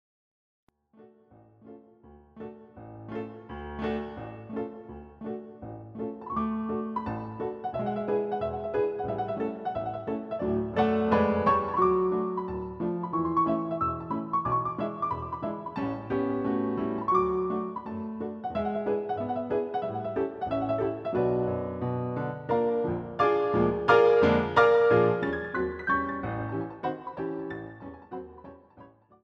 using the stereo sampled sound of a Yamaha Grand Piano